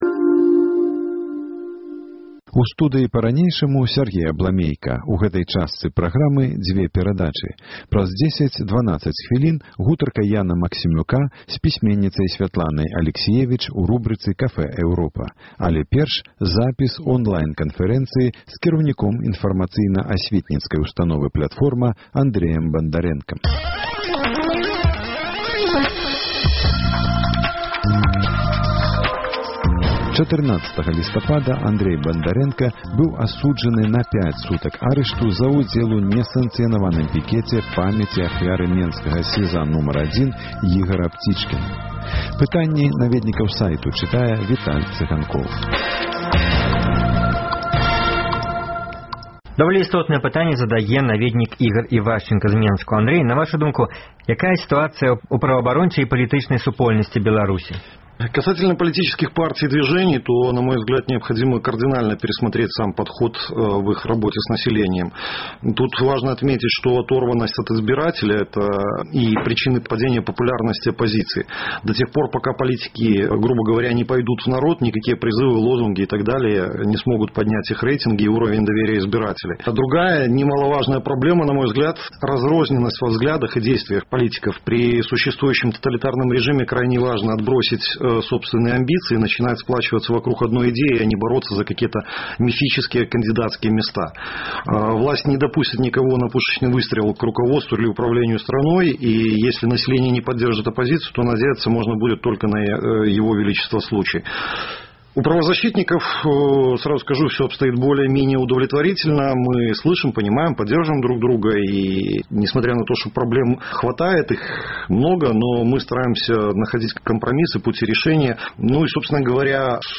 Радыёварыянт онлайн-канфэрэнцыі праваабаронцы